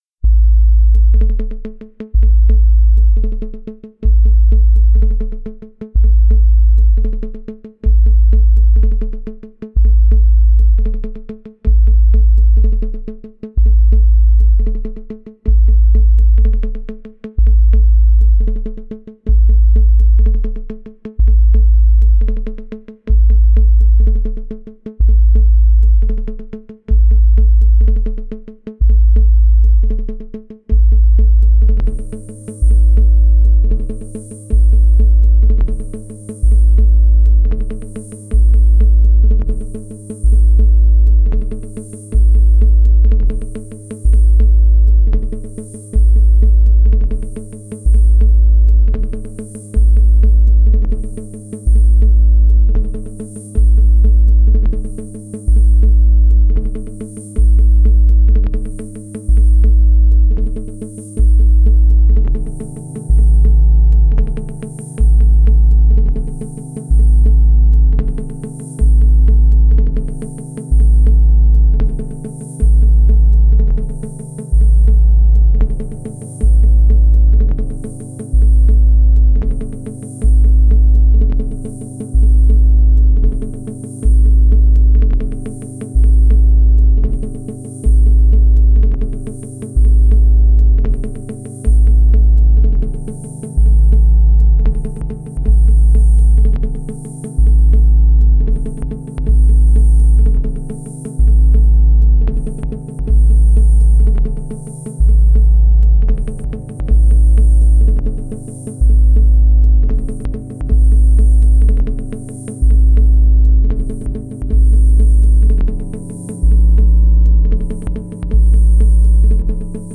experimental electronic techno minimal